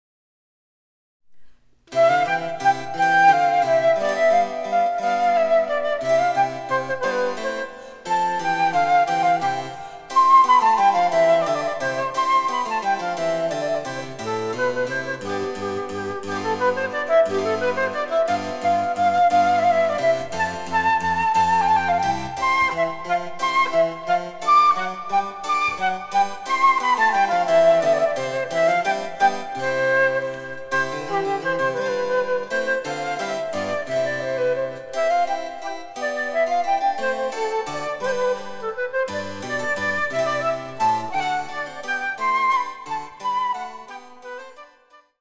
明るくのびやかな旋律、素朴な牧歌性と都会的な洗練との独特な混交の魅力はここでも健在です。
■フルートによる演奏
チェンバロ（電子楽器）